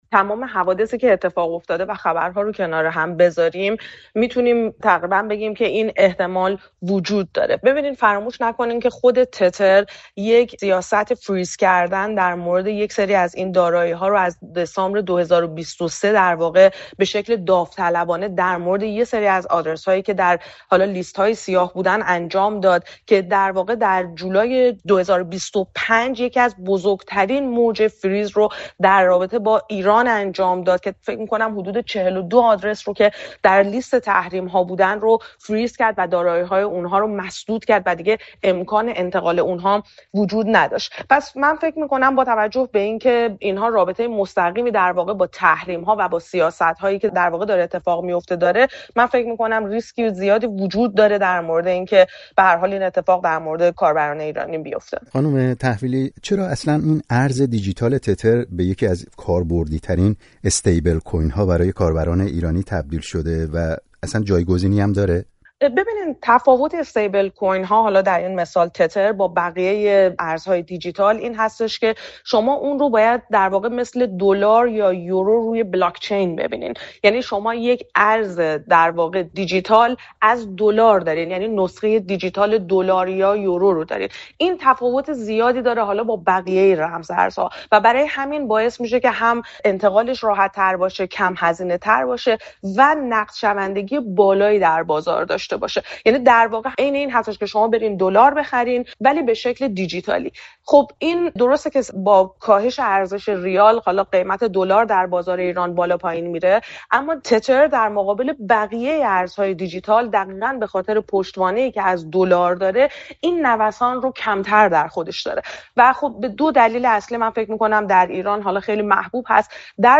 برنامه‌های رادیویی